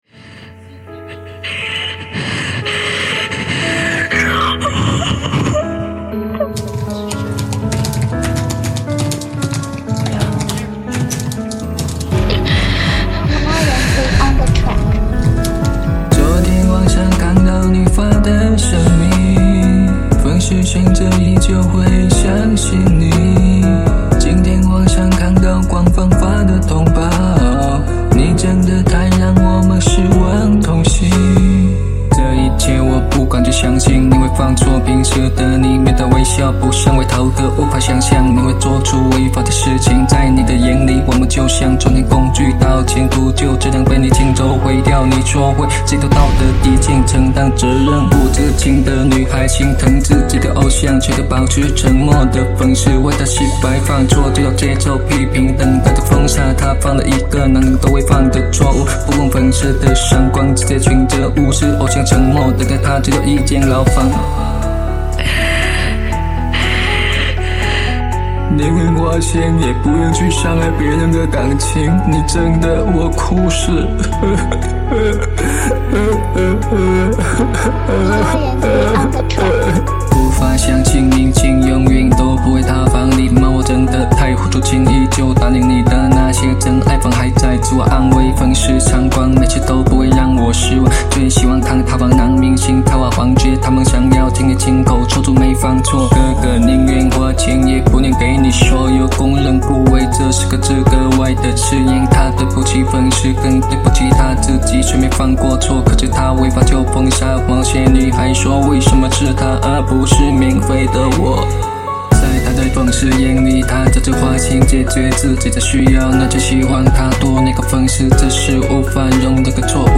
（女孩哭泣声音
电脑打字声）